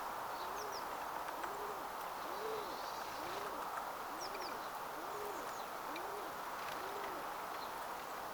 kesykyyhkyn laulua
Kesykyyhkyjä oli puussa korkealla 13
ja ne lauloivat ahkeraan.
kesykyyhkyn_laulua.mp3